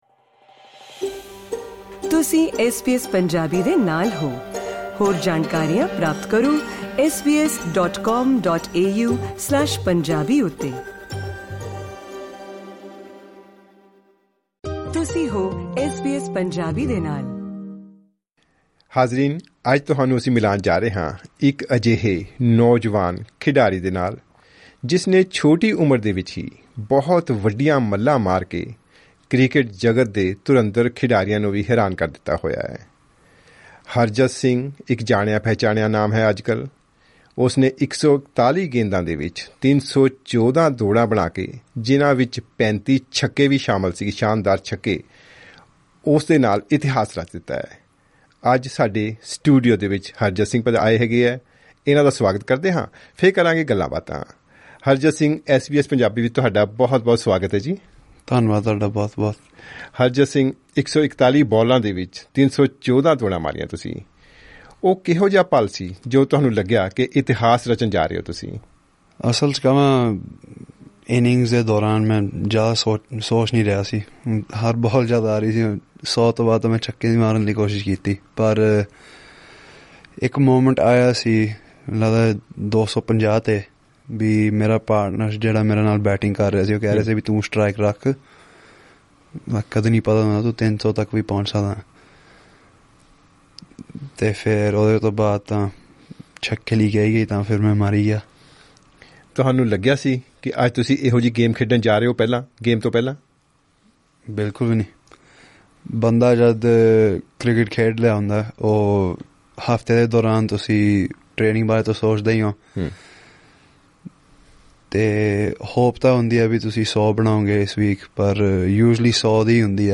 Click on the audio icon to listen to the full report and interview in Punjabi.